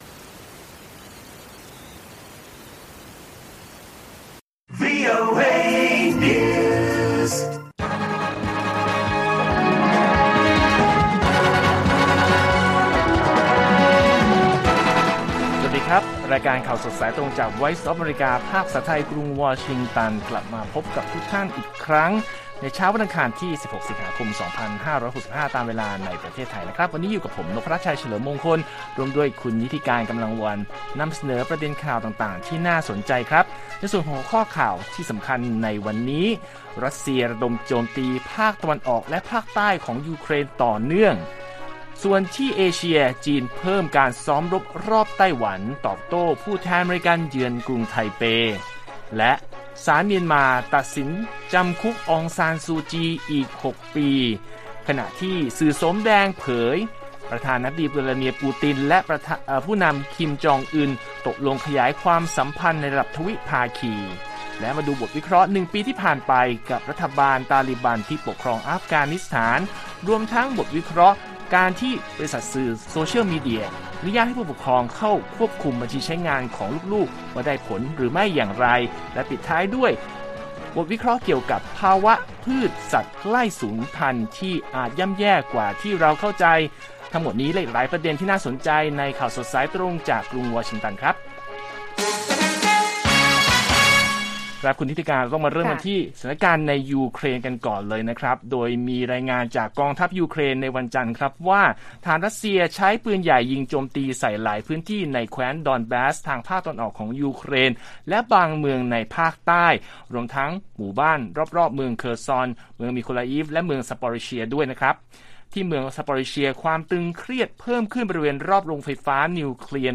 ข่าวสดสายตรงจากวีโอเอไทย อังคาร 16 ส.ค. 65